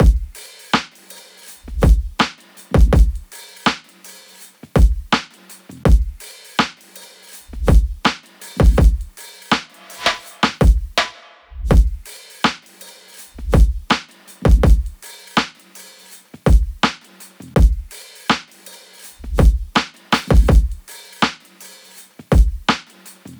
CALL LOGS DRUM LOOP 82 BPM.wav